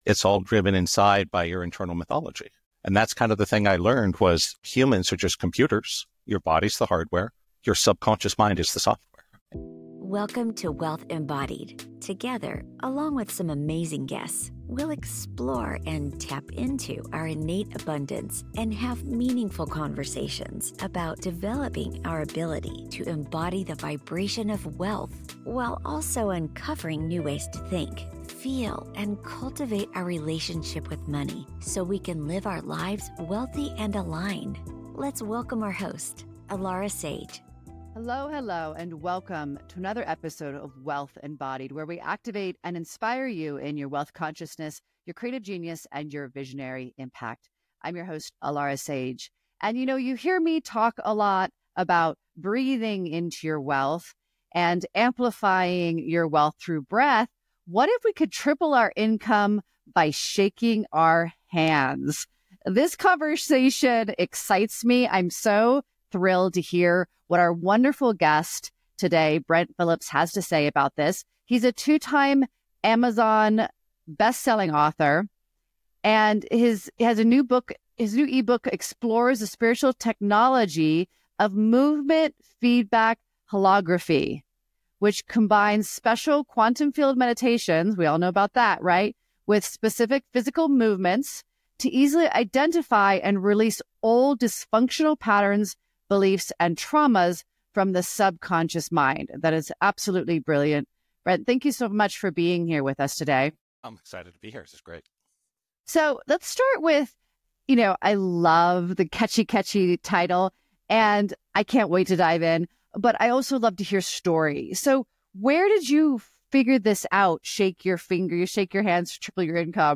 Conversations and guest interviews to help you learn how to activate wealth consciousness, embody the frequency of wealth and apply financial strategies to your life. Listen to compelling conversations and insights on Quantum Mechanics, the Law of Attraction, Manifestation, and Wealth Creation and Management.